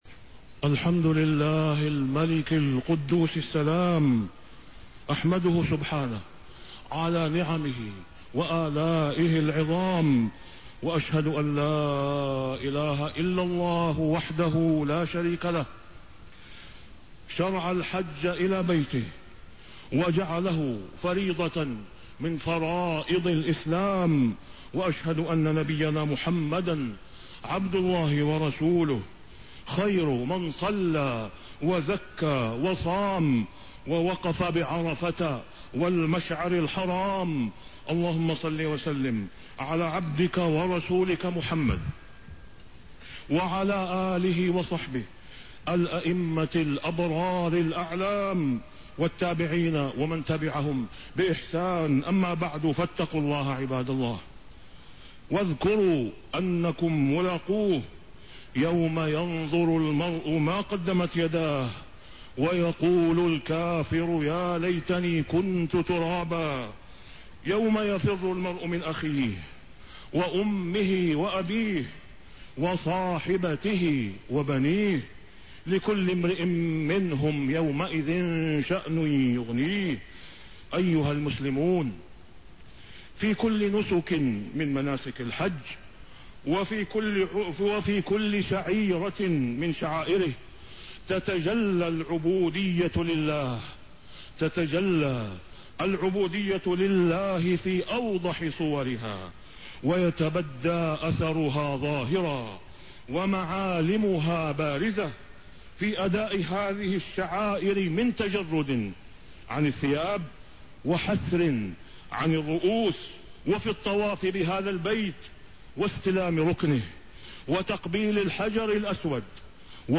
تاريخ النشر ٢١ ذو القعدة ١٤٣٤ هـ المكان: المسجد الحرام الشيخ: فضيلة الشيخ د. أسامة بن عبدالله خياط فضيلة الشيخ د. أسامة بن عبدالله خياط مظاهر العبودية لله في الحج